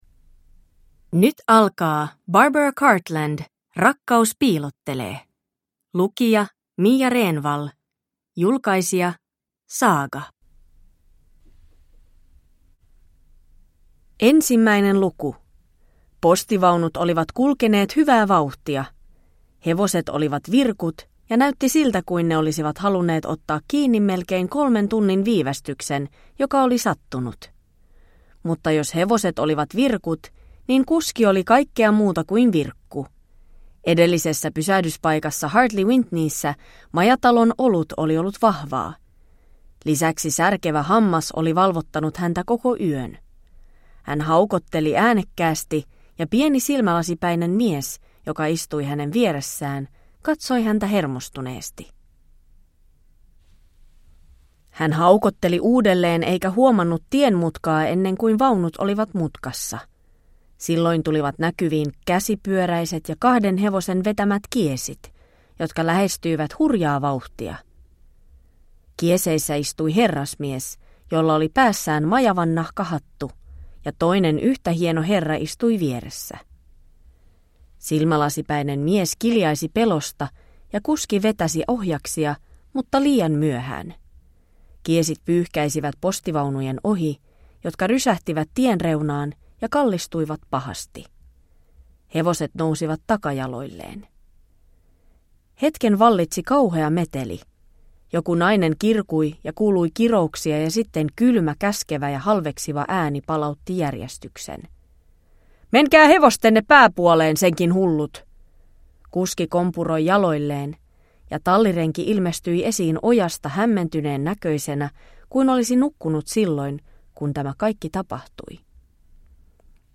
Rakkaus piilottelee / Ljudbok